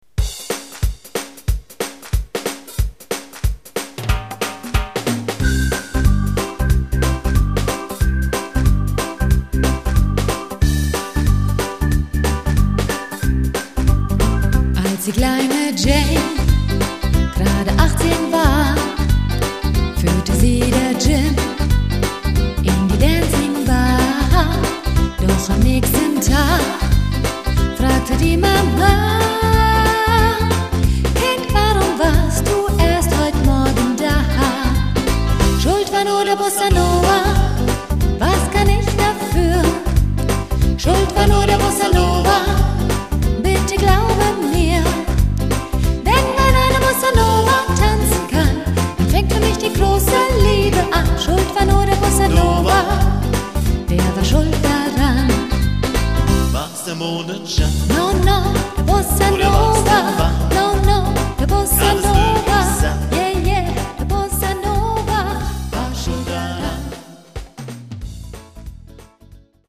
Tanz-& Unterhaltungskapelle